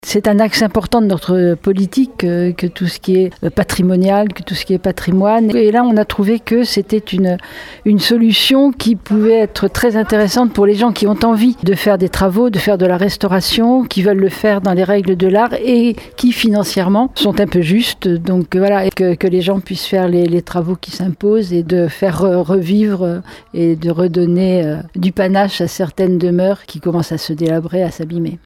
Pour bénéficier de ces aides, les propriétaires doivent respecter certains critères et recevoir un avis favorable des Architectes des bâtiments de France. L’objectif pour la Ville est de redonner plus d’éclat à son centre historique comme le souhaite la maire de Surgères Catherine Desprez :